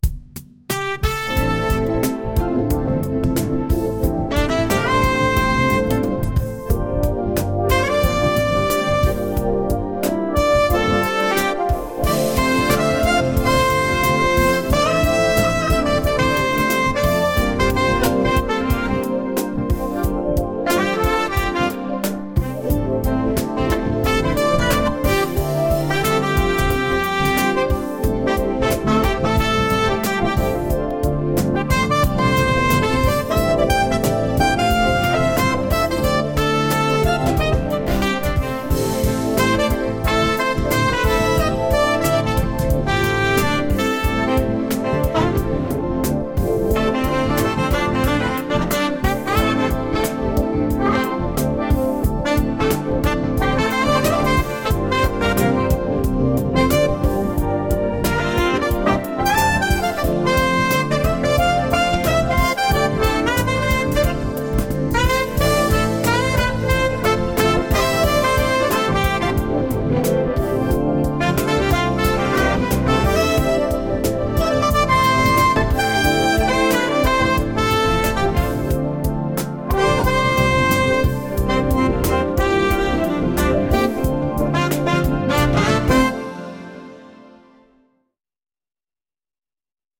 おしゃれ
コミカル